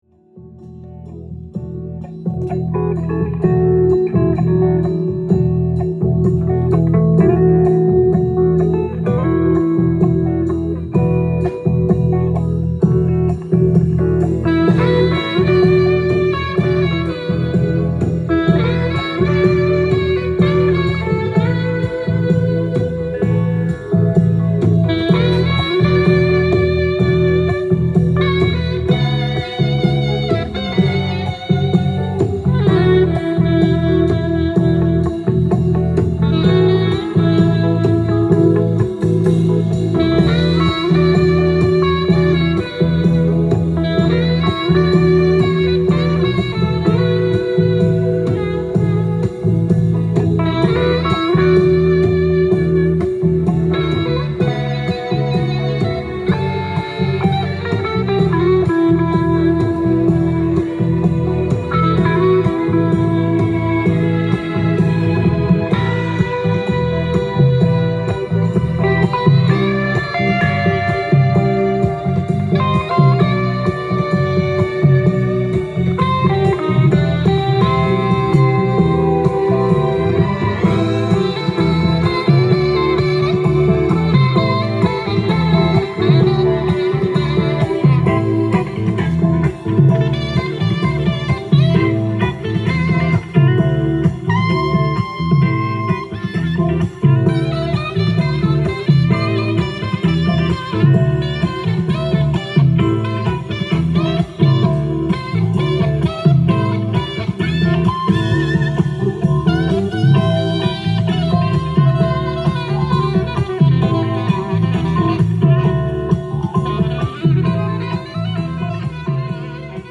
店頭で録音した音源の為、多少の外部音や音質の悪さはございますが、サンプルとしてご視聴ください。
ちょっぴりフュージョンなジャズ・ファンクを収録した本作。